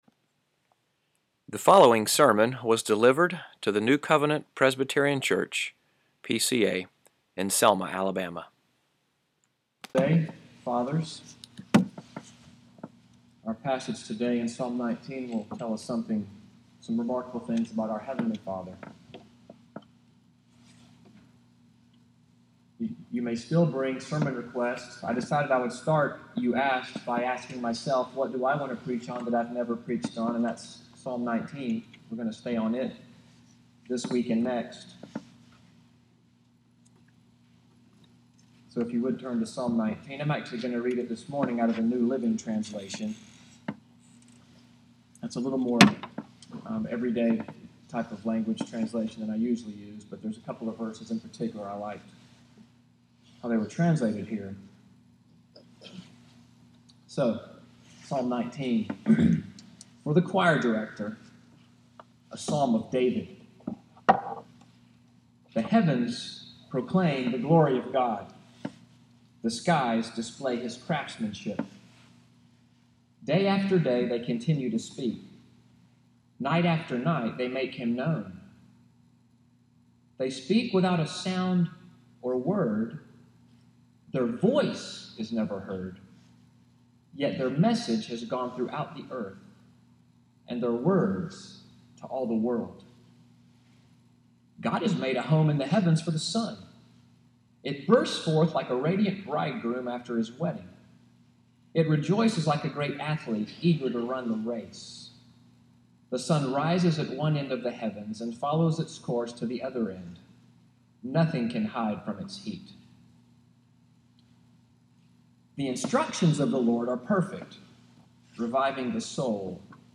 SUNDAY MORNING WORSHIP at NCPC, June 18, 2017, audio of the sermon “Divine Revelation”